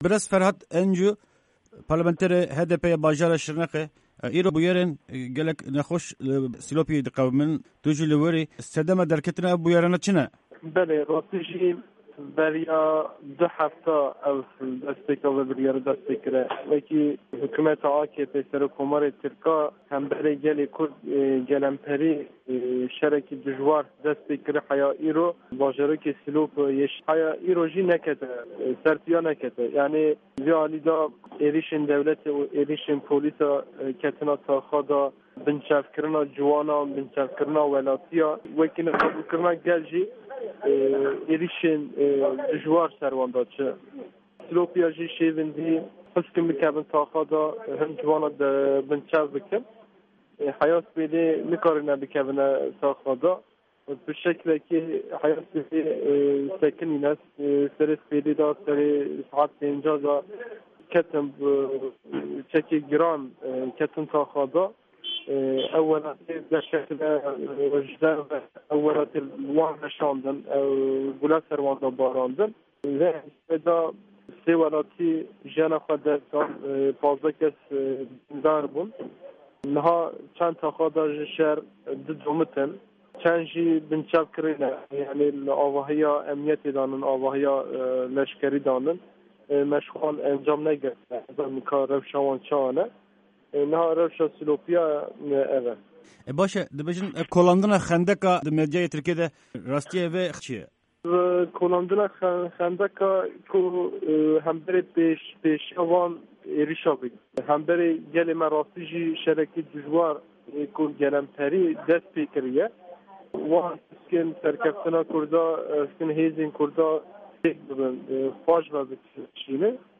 Parlamanterê HDPê yê bajarê Şirnêxê Ferhat encu di vê hevpeyvînê de agahîyên herî dawî ji herêmê dide.